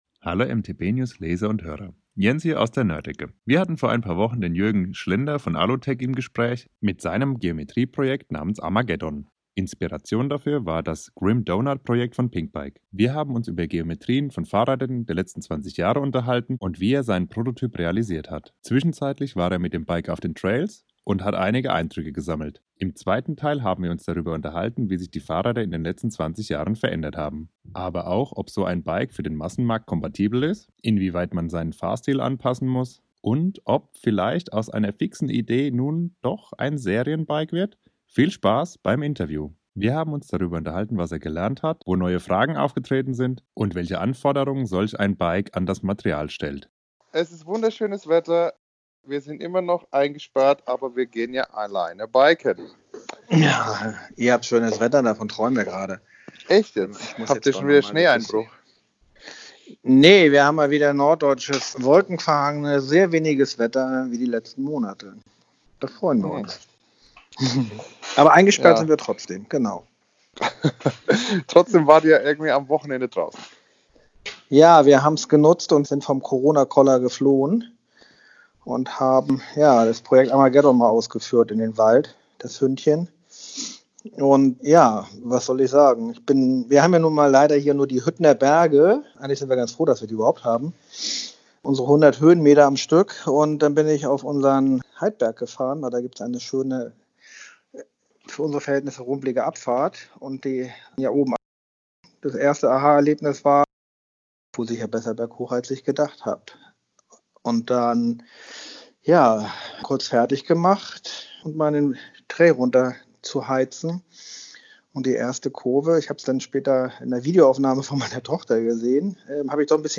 Alutech Armageddon – Interview Teil 2